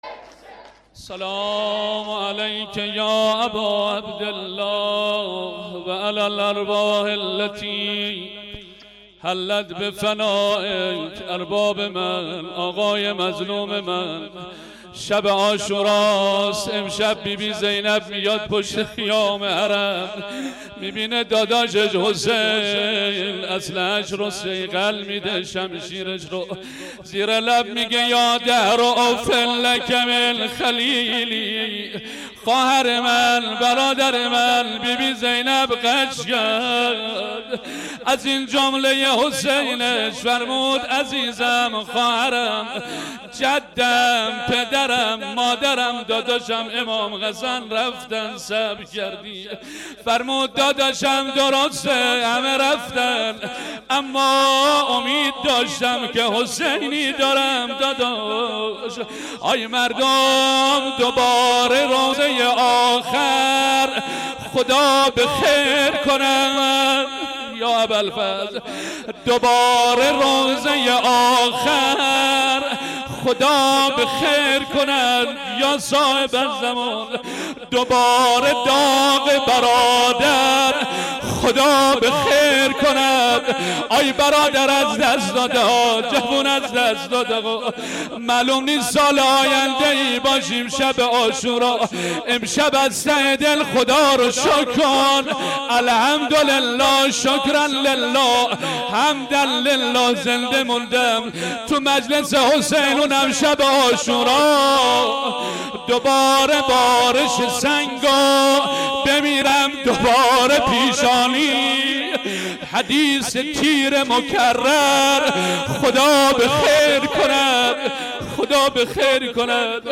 • شب10 محرم93 هیئت ثارالله علیه السلام - روضه خوانی.mp3
شب10-محرم93-هیئت-ثارالله-علیه-السلام-روضه-خوانی.mp3